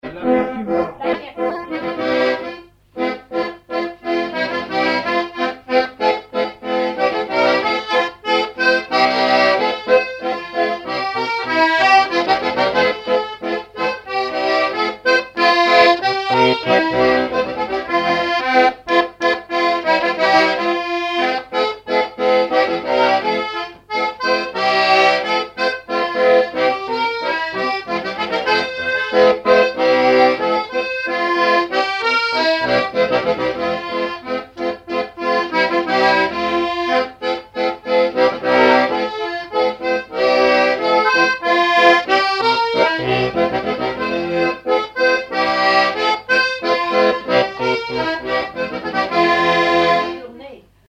Localisation Noirmoutier-en-l'Île (Plus d'informations sur Wikipedia)
Thème : 1074 - Chants brefs - A danser
Fonction d'après l'analyste danse : scottich sept pas ;
Genre brève
Catégorie Pièce musicale inédite